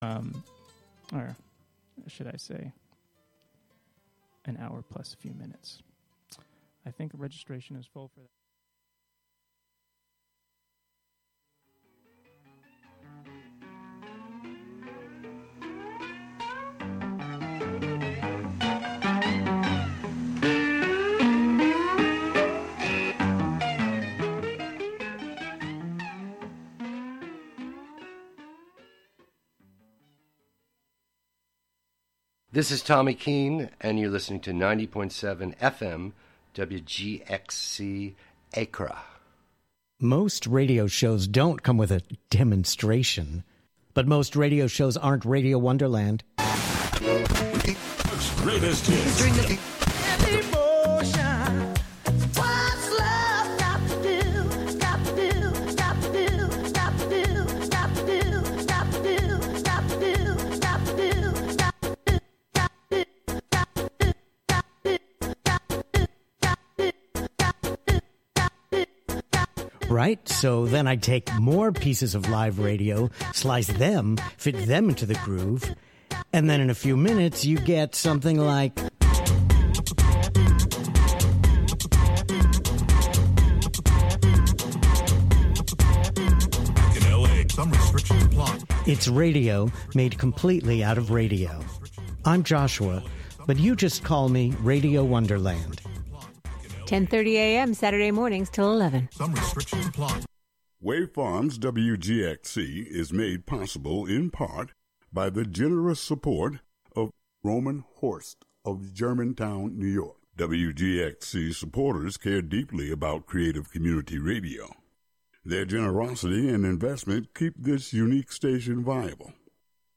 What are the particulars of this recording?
Broadcasting live from either The Spark of Hudson , the Hudson Thursday Market , or other locations out and about in the community.